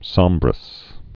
(sŏmbrəs)